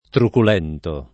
truculento [ trukul $ nto ] agg.